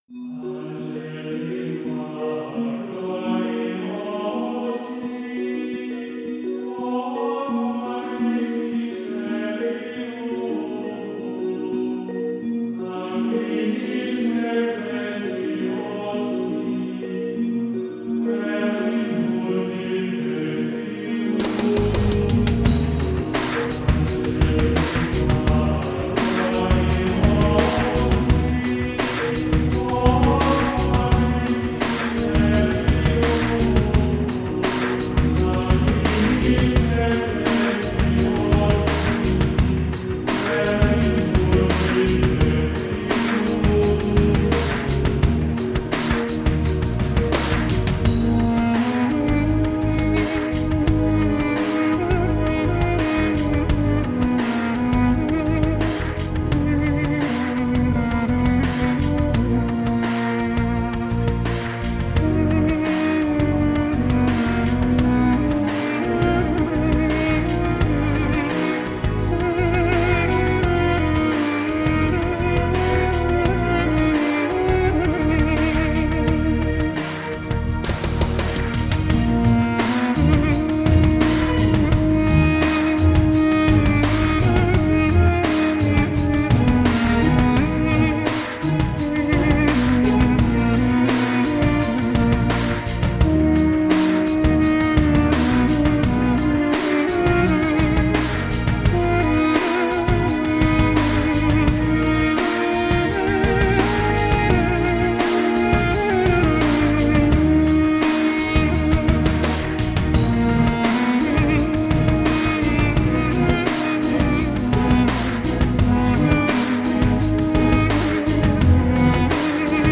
Genre: Oriental, Ethnic, World, Duduk, New Age
guitar
kamancha